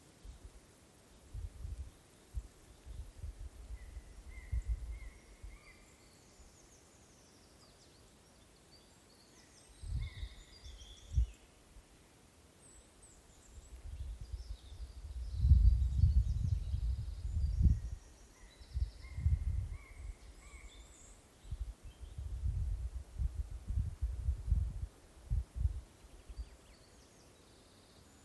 Bird Aves sp., Aves sp.
Administratīvā teritorijaAlūksnes novads
StatusVoice, calls heard